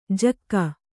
♪ jakka